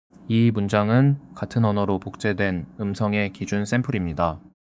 한국어(same-language) 클로닝은 초반이 약간 어색한데 뒤로 갈수록 자연스러워졌어요.
clone/00_korean_control — 같은 한국어 ref 그대로 같은 언어로 복제
clone_00_korean_control.wav